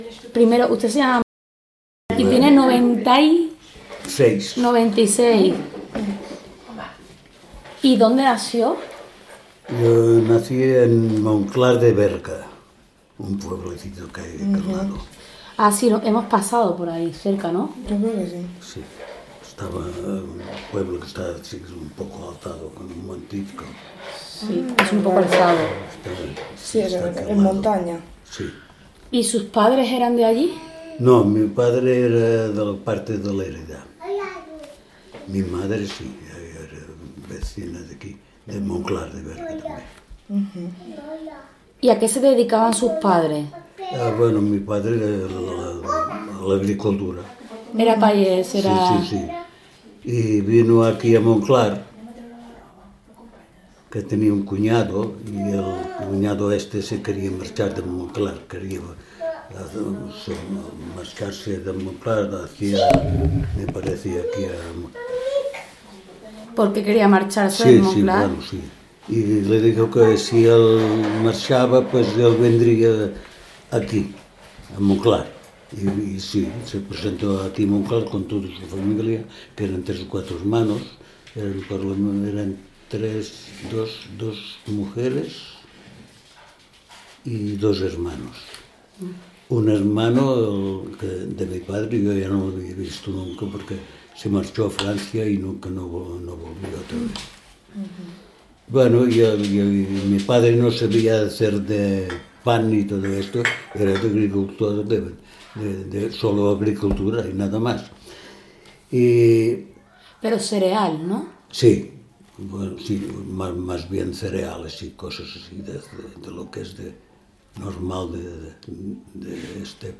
Enclave L'Espunyola
Encuesta